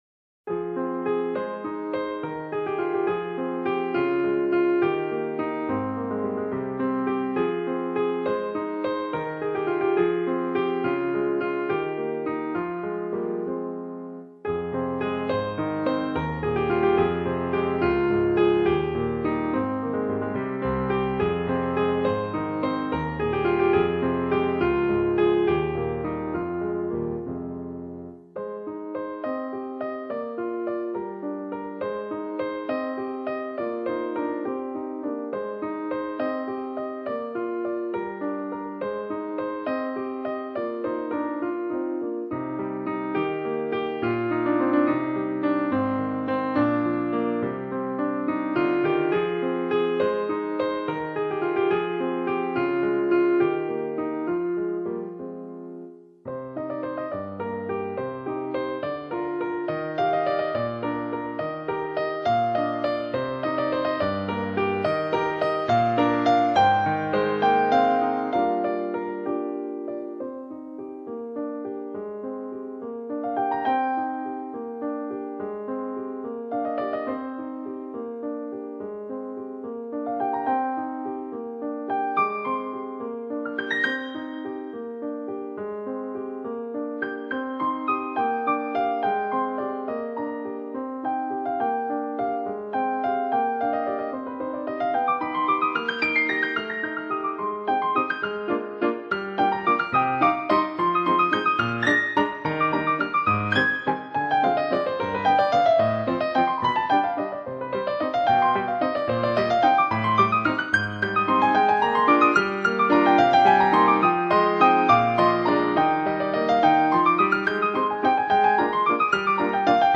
器乐演奏家系列
录音地点：中央音乐学院小演奏厅